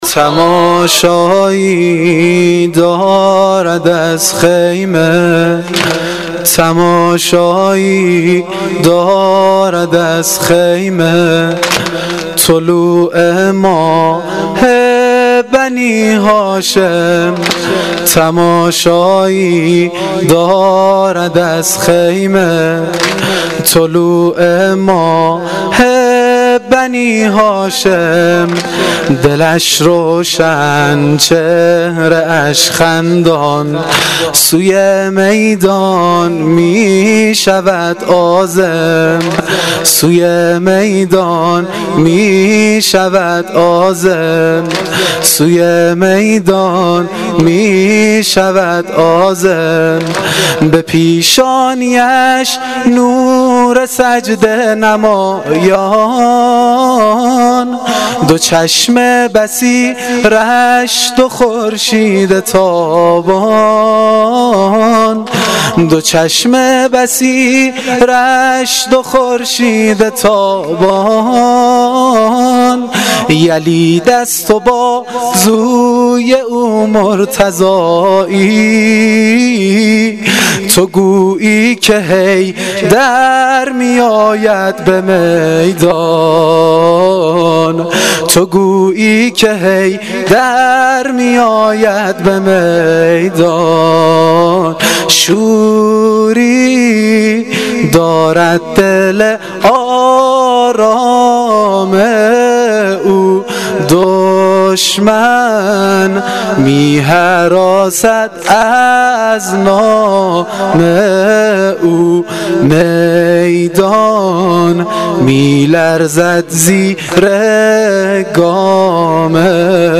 واحد سنگین شب نهم محرم الحرام 1396 (شب تاسوعا)
روضه